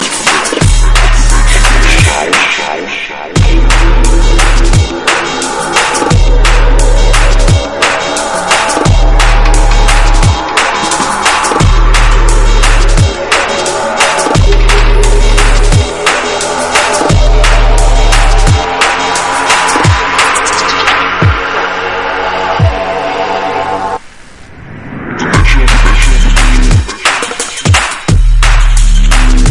TOP >Vinyl >Drum & Bass / Jungle
TOP > Jump Up / Drum Step